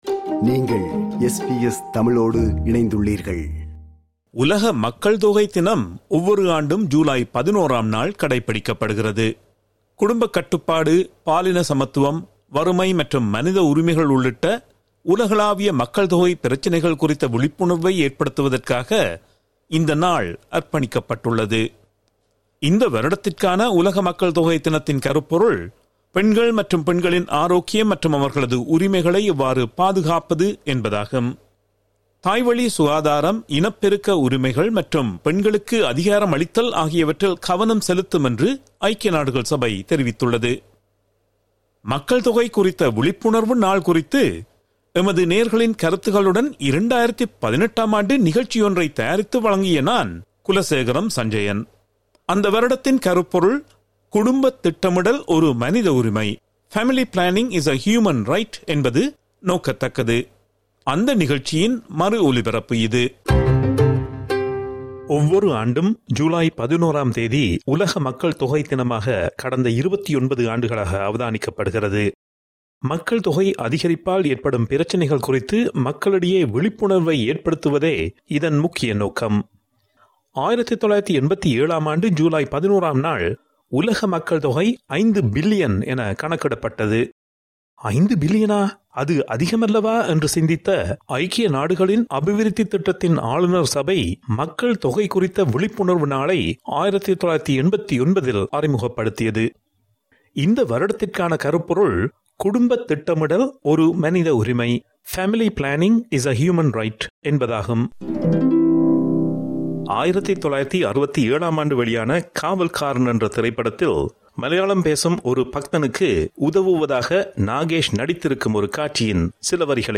எமது நேயர்களின் கருத்துகளுடன்